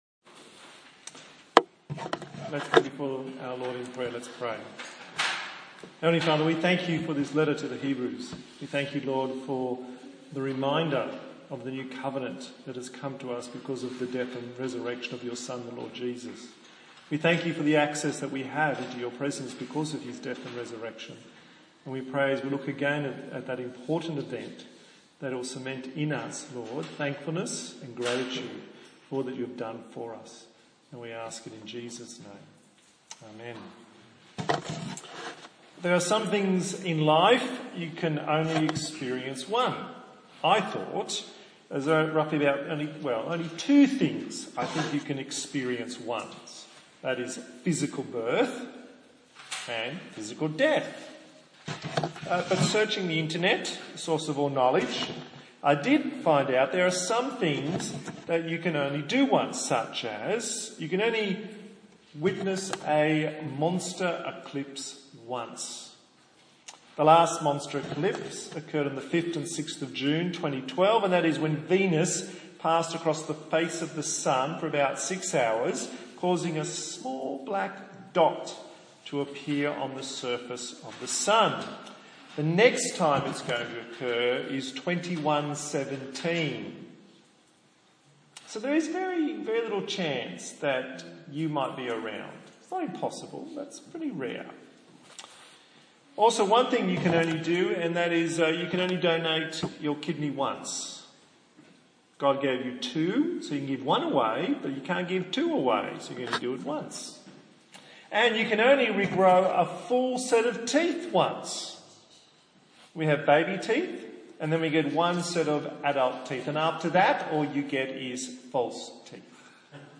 15/05/2016 Once for All Preacher